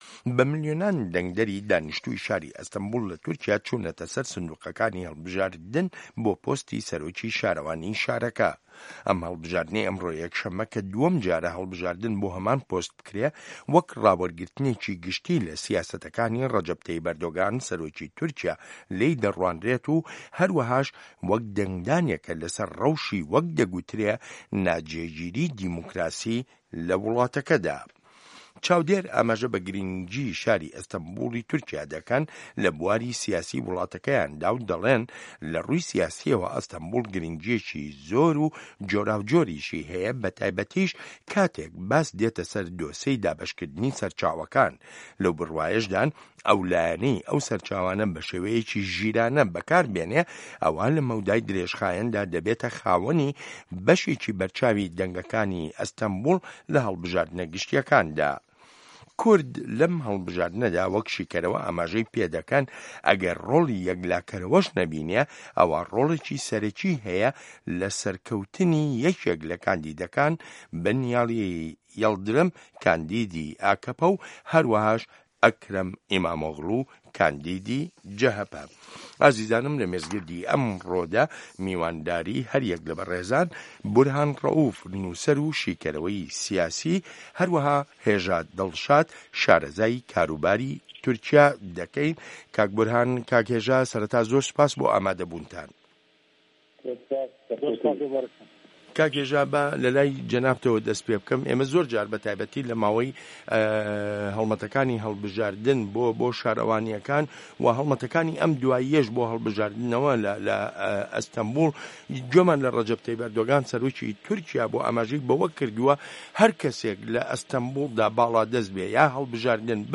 مێزگرد: ئەستانبوڵ لە هاوکێشەی سیاسی تورکیادا